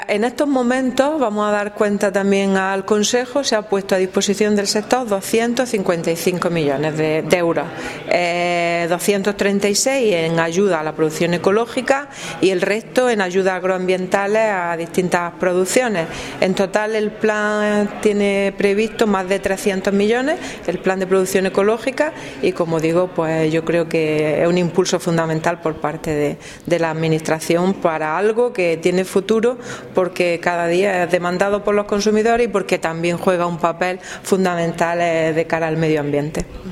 La consejera, en la reunión del Consejo Andaluz de la Producción Ecológica.
Declaraciones consejera CAPE